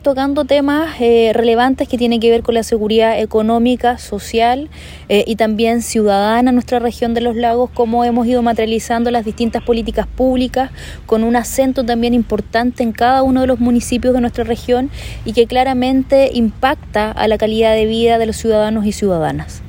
La delegada Presidencial Regional, Paulina Muñoz, conversó en segundo lugar con el mandatario y sin profundizar en los temas, se refirió a las generalidades dialogadas con el presidente Boric, las que se enfocaron en seguridad económica, social y ciudadana, como la materialización de políticas públicas.